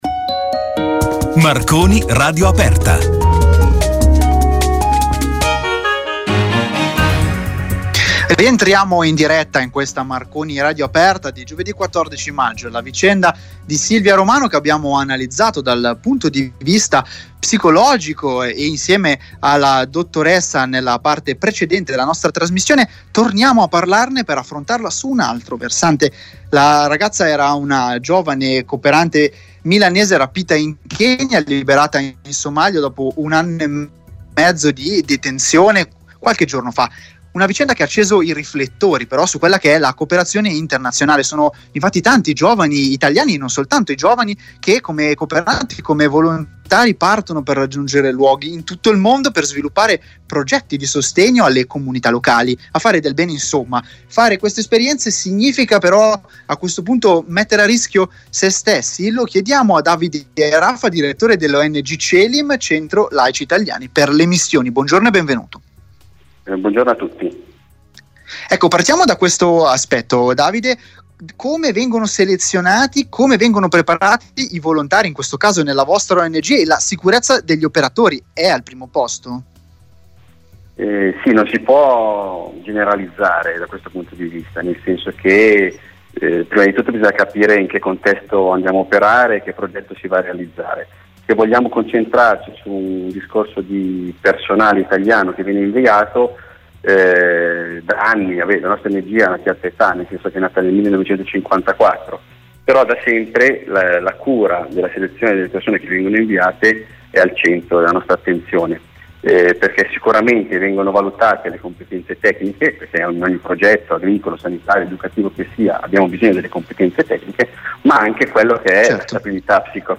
Radio Aperta – Intervista